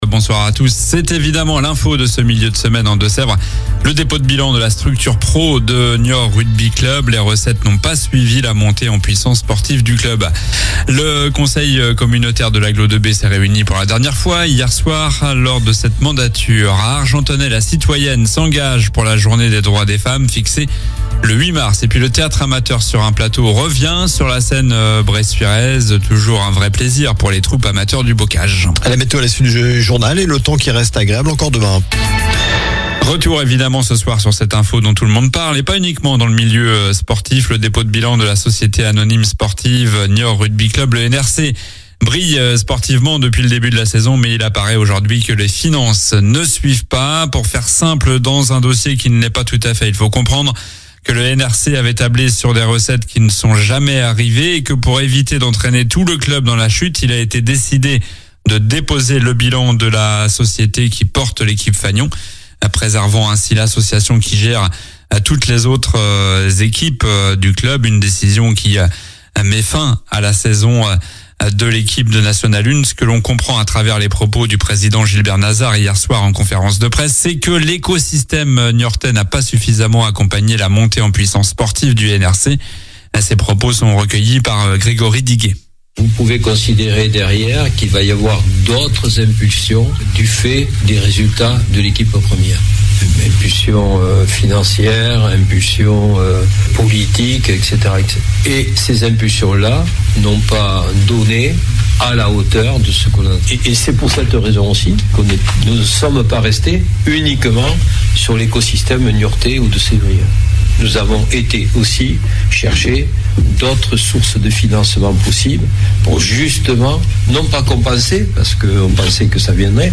Journal du mercredi 4 mars (soir)